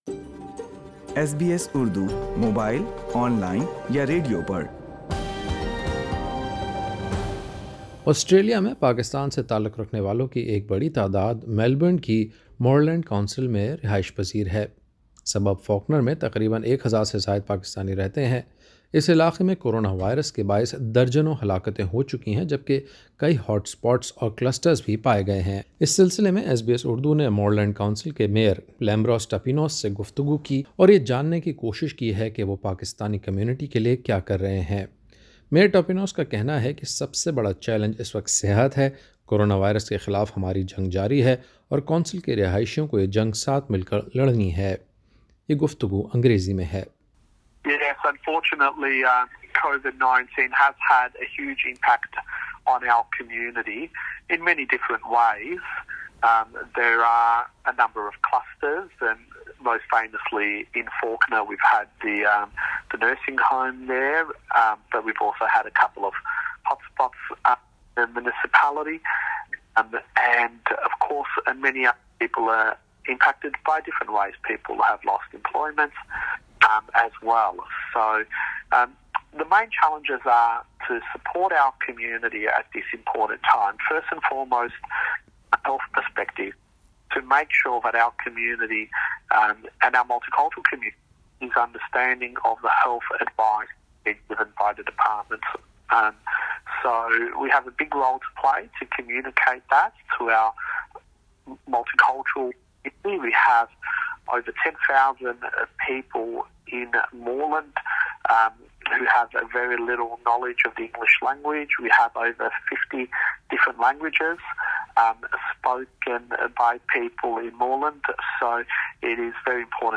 ایس بی ایس اردو نے مورلینڈ کونسل کے مئیر لیمبروس ٹاپینوس سے کمیونٹی کے مسائل کے بارے میں گفتگو کی ہے۔